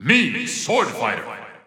The announcer saying Mii Swordfighter's name in English and Japanese releases of Super Smash Bros. Ultimate.
Category:Mii Fighter (SSBU) Category:Mii Swordfighter (SSBU) Category:Announcer calls (SSBU) You cannot overwrite this file.
Mii_Swordfighter_English_Announcer_SSBU.wav